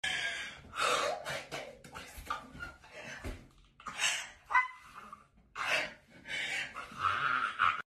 Barbie fake laugh
Barbie-fake-laugh.mp3